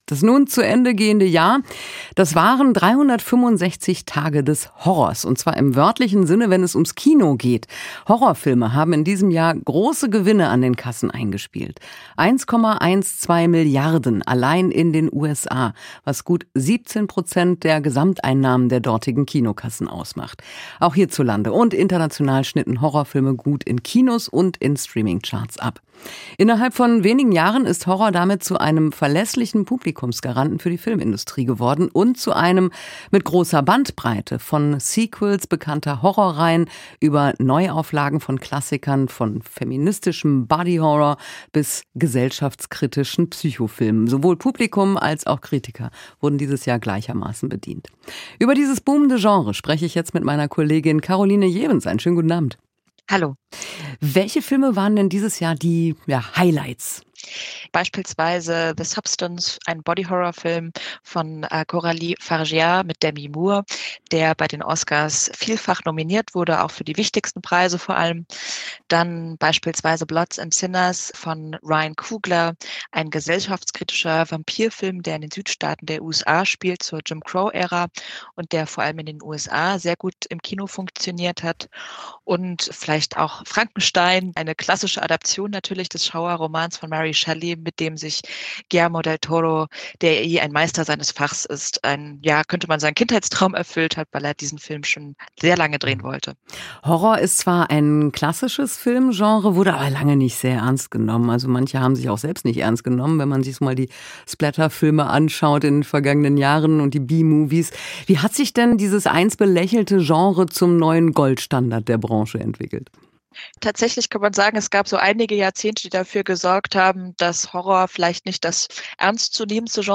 Gespräch mit